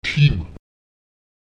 Lautsprecher tim [tim] stehen